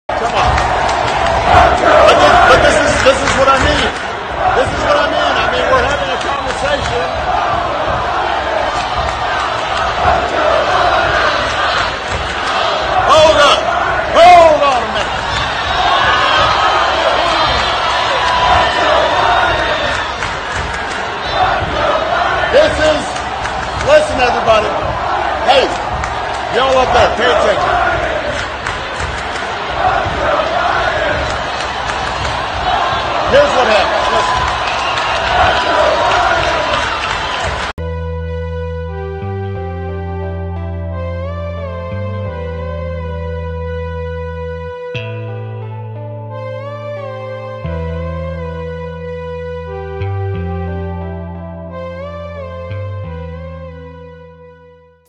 Obama speech ruined by F*ck Joe Biden Chant!